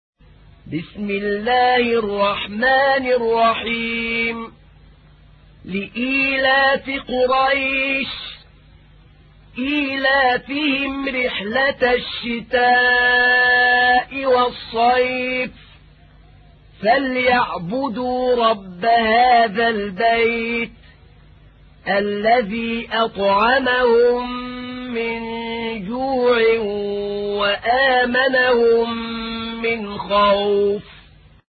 تحميل : 106. سورة قريش / القارئ أحمد نعينع / القرآن الكريم / موقع يا حسين